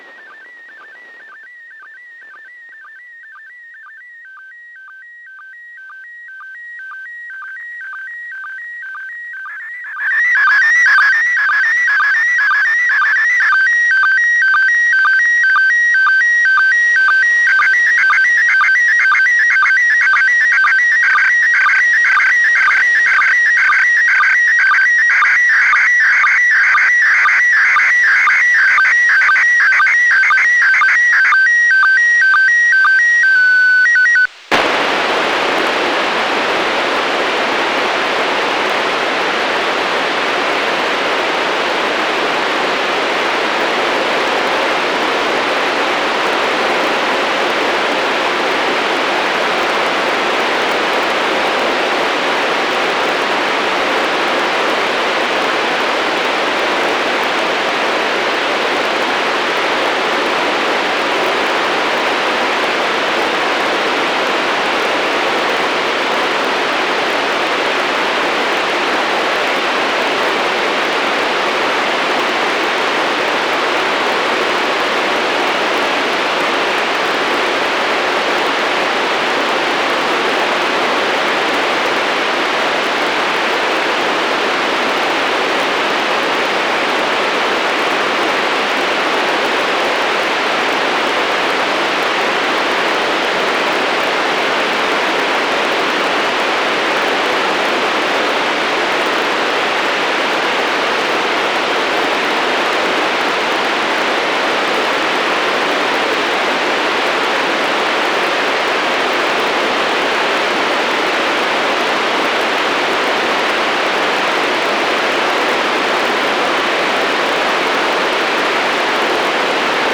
the ISS presently sends SSTV pictures.  I recorded what my receiver
getting ready), then noise when the ISS did not transmit, and then a
nice fragment of another picture that's slowly decaying into noise.